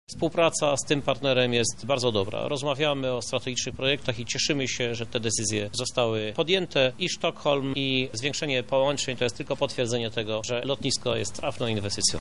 i turystyczny Lublina – mówi Krzysztof Żuk prezydent Lublina: